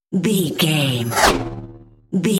Pass by sci fi fast
Sound Effects
Fast
futuristic
intense
pass by
car